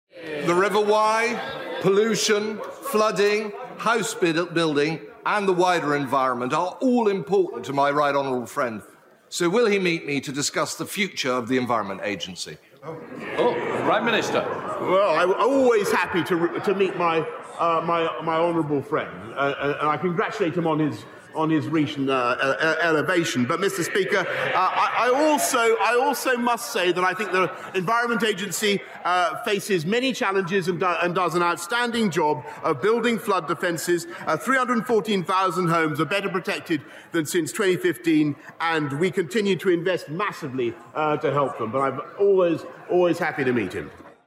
Mr Wiggin raised the issue during today's Prime Minister Questions.
The PM responded thanking Sir Bill Wiggin for his work and that he would be happy to meet him, or for him to meet the appropriate Secretary of State.